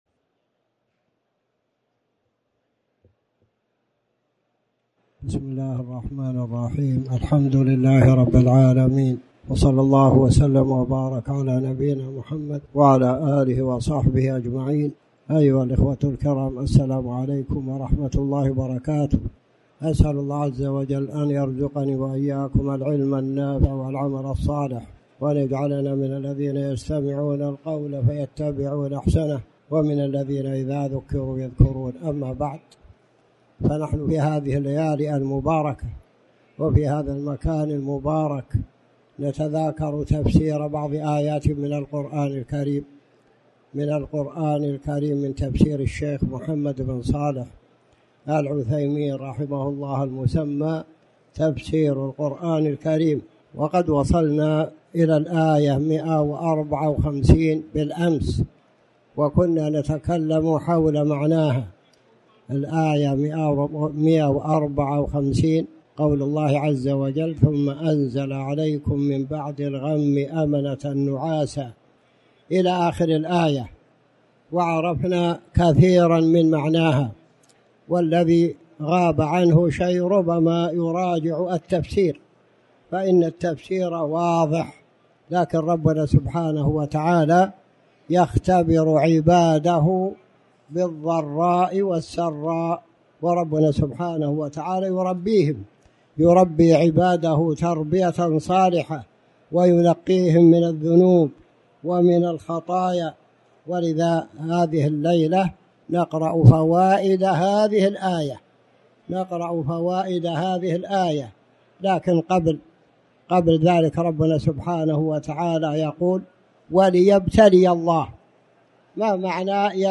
تاريخ النشر ٢٠ رمضان ١٤٣٩ هـ المكان: المسجد الحرام الشيخ